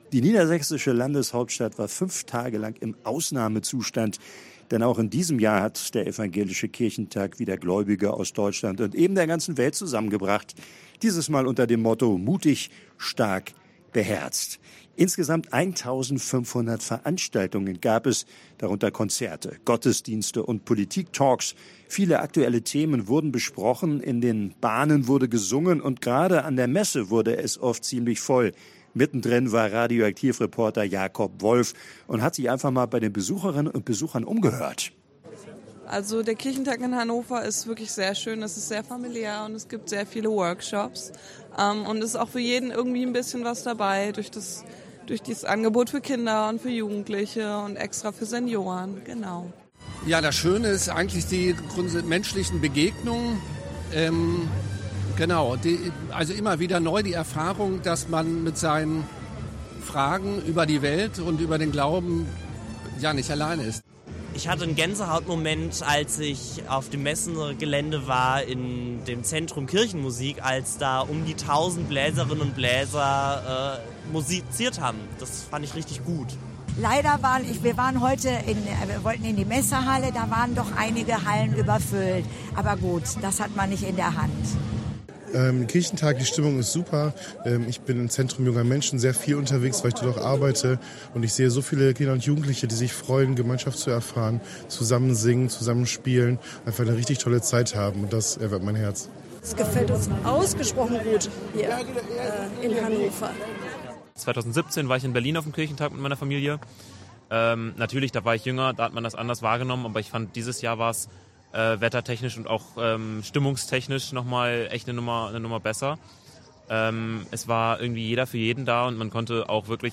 Umfrage: So haben die Besucher den Kirchentag in Hannover erlebt
umfrage-so-haben-die-besucher-den-kirchentag-in-hannover-erlebt.mp3